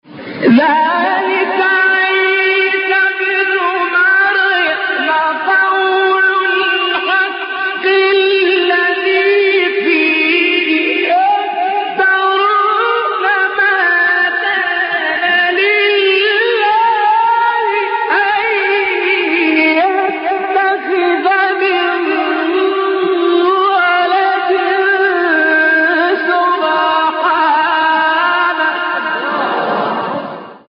مقام : چهارگاه